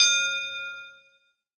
Bell Hit 1